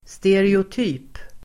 Uttal: [stereot'y:p]